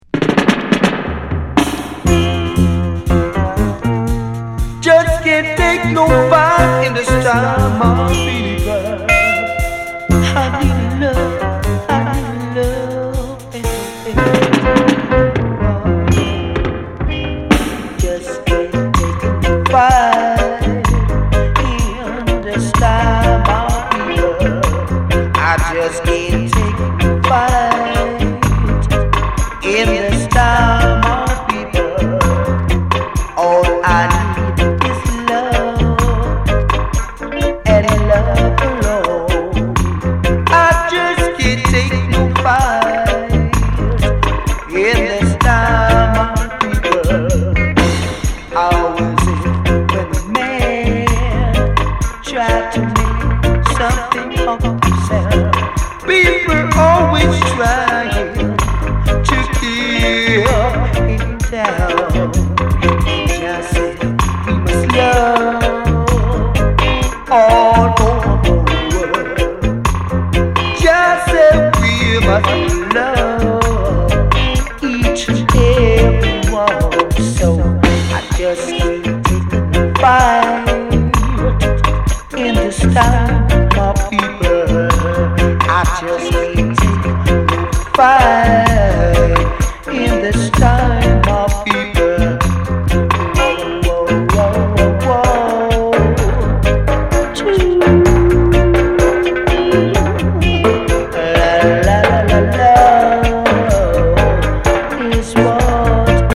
スウィートな中にも熱さが光る素晴らしい内容です。